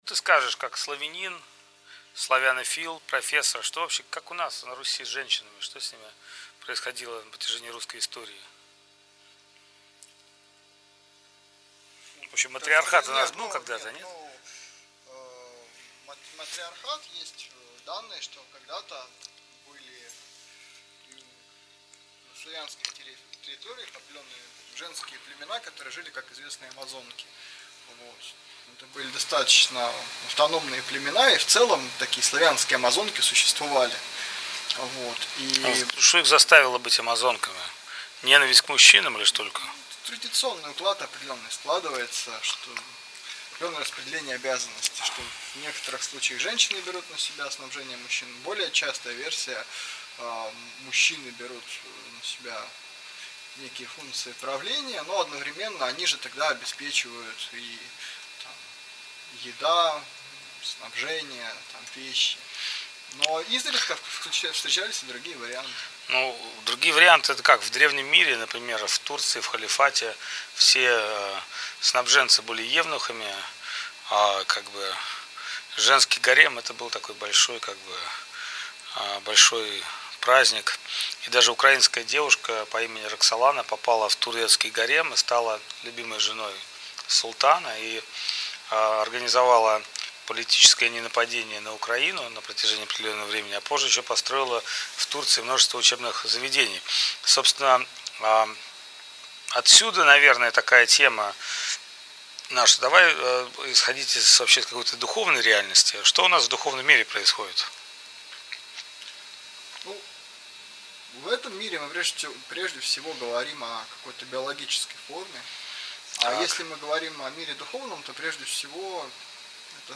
проводят интервью с преданными, задавая им непростые вопросы на тему семьи, любви и духовной жизни.